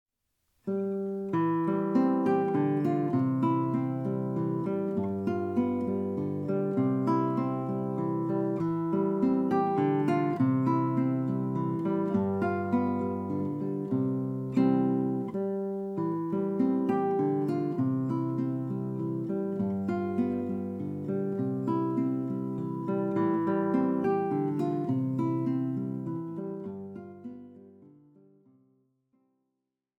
Gitarrenmusik aus Wien von 1800-1856
Besetzung: Gitarre